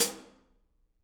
R_B Hi-Hat 01 - Close.wav